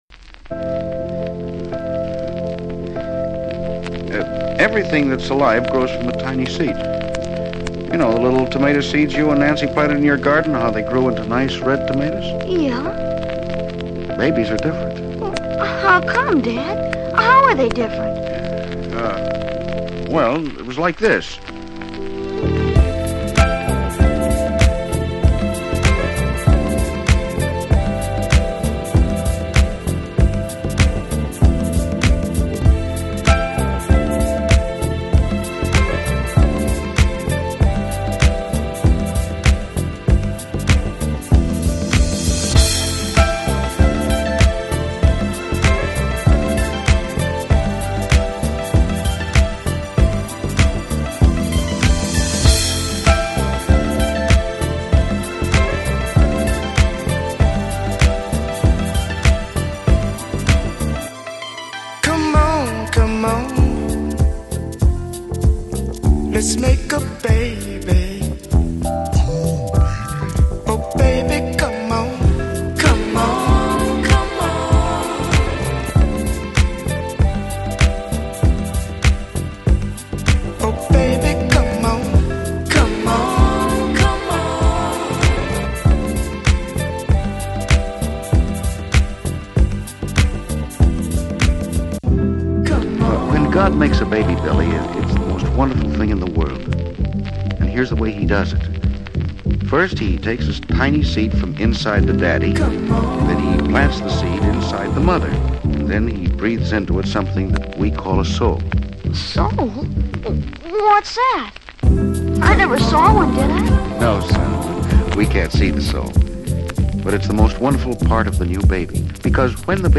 Smooth Soul, Nu Disco